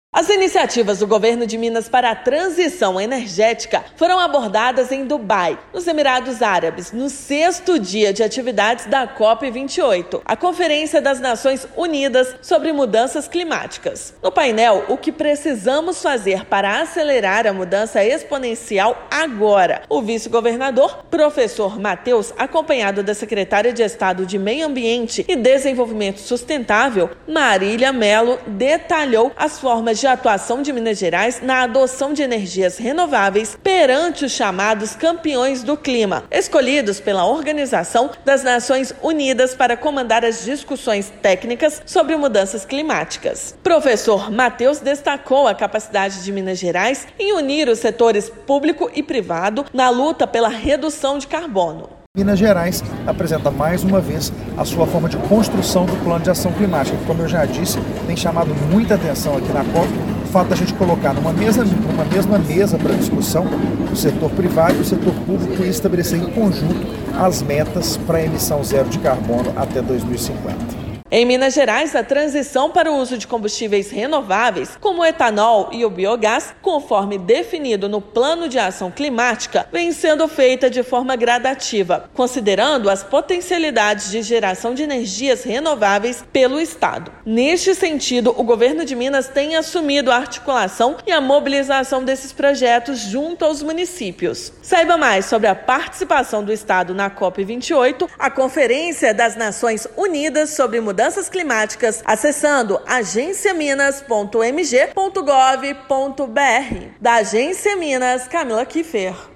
Vice-governador trouxe panorama mineiro dentre formas de governos locais e iniciativa privada trabalharem para aplicar as mudanças em diversos setores. Ouça matéria de rádio.